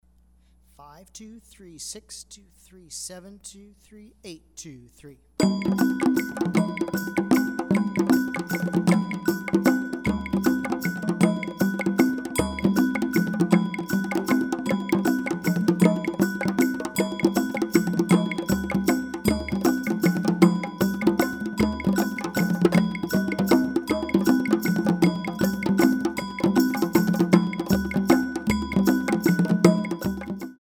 Slow triplets